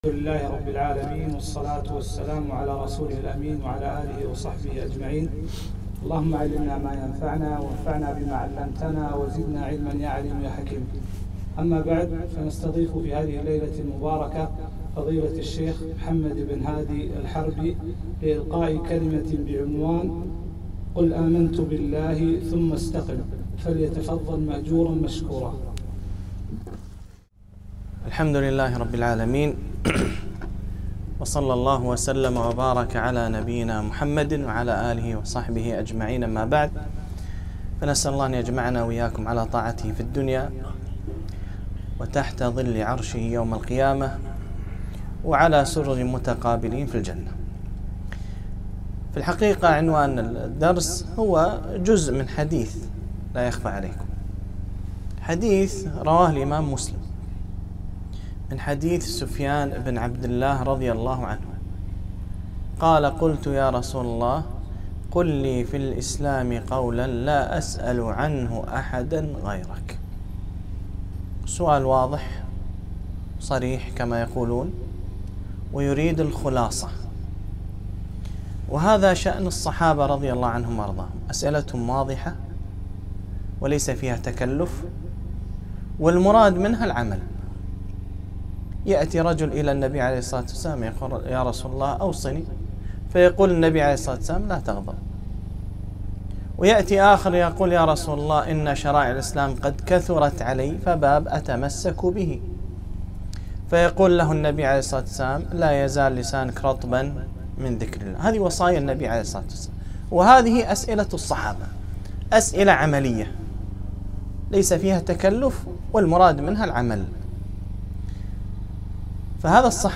محاضرة - قل آمنت بالله ثم استقم